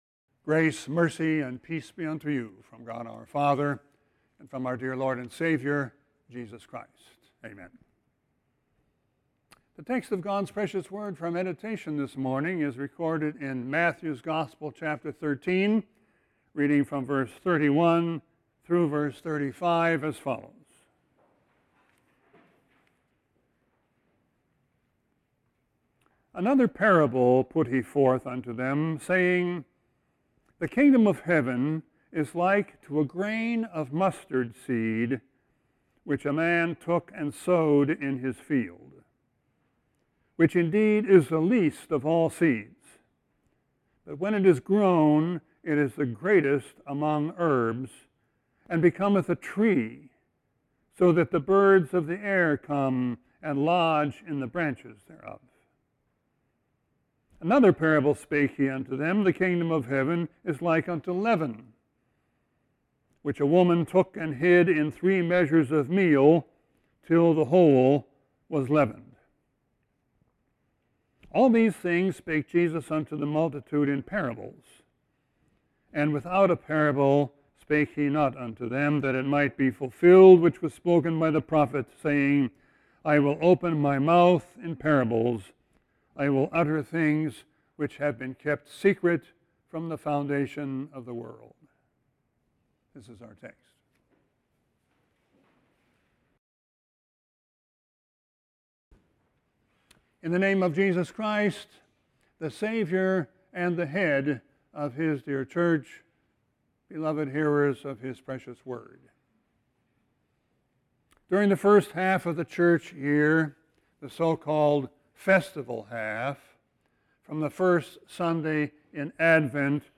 Sermon 6-6-21.mp3